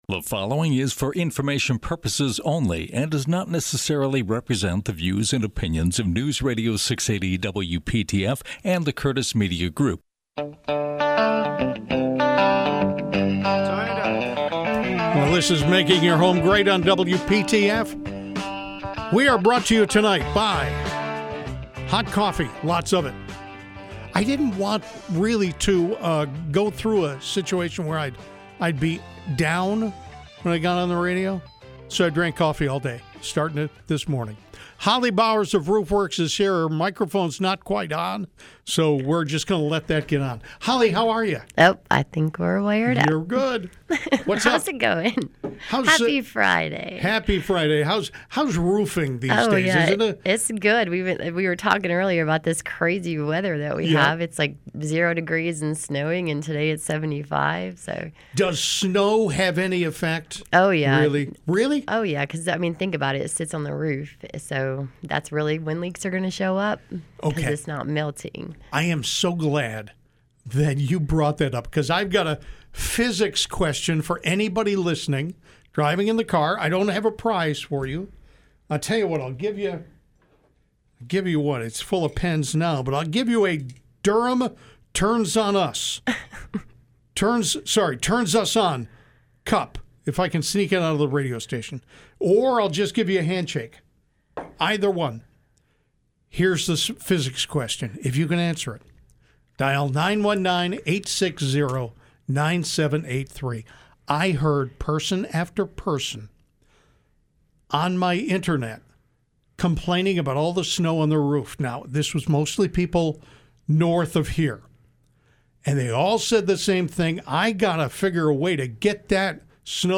Now there is a local call-in show for all your home design, home improvement, renovation and addition questions. Each week we will have co-hosts who are also experts in different areas of home improvement.